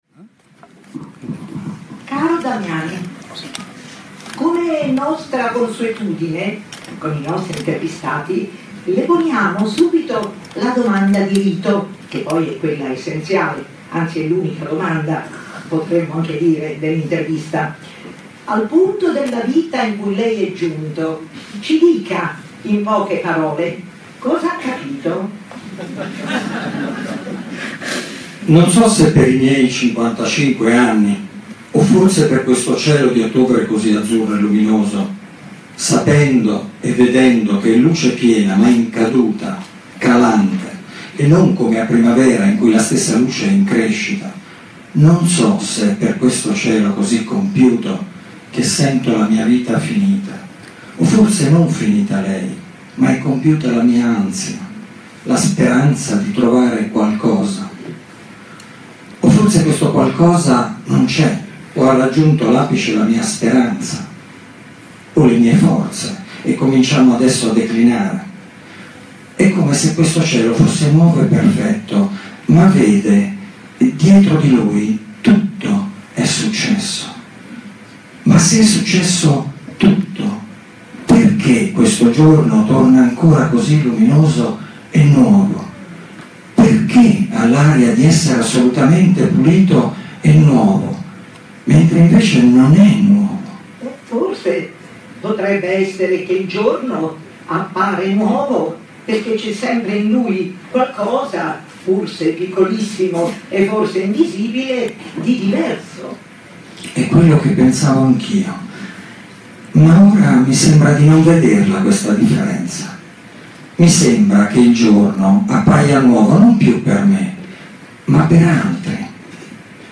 Nanni Moretti e Piera Degli Esposti leggono testi da Cieli celesti